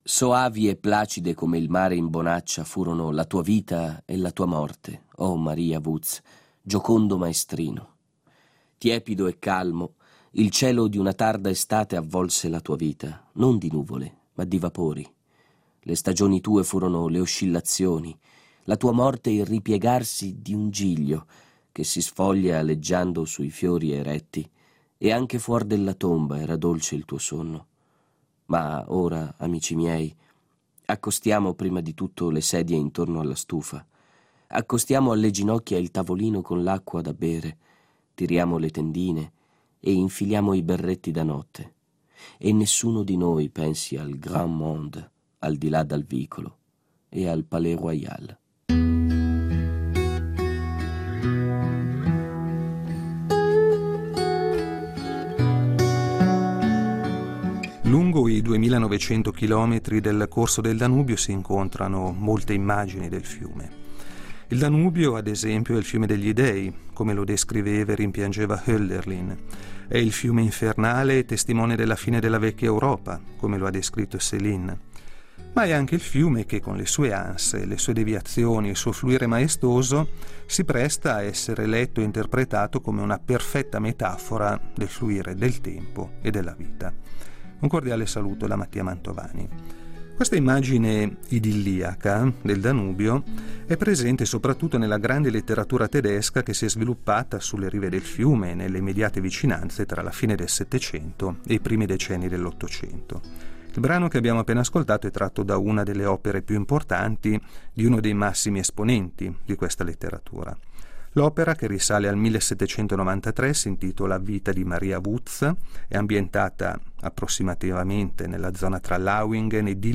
La quarta puntata sarà ambientata in Baviera, nella zona tra Lauingen e Dillingen, con la lettura di un altro grande classico della letteratura tedesca, Jean Paul, e del suo celebre racconto Vita di Maria Wuz, dal quale emerge l’idea del Danubio come «fiume del tempo».